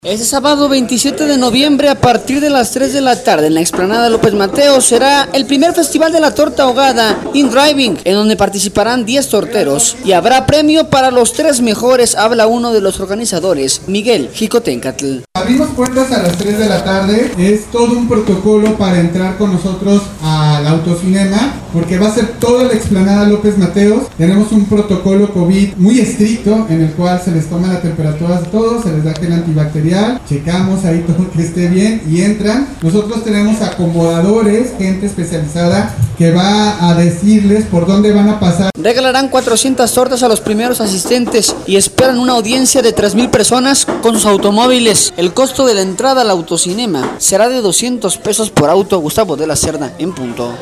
Este sábado 27 de noviembre a partir de las 3 de la tarde en la Explanada López Mateos, será el primer festival de la torta ahogada Im Driving, en donde participarán 10 torteros, y habrá premio para los tres mejores. Habla uno de los organizadores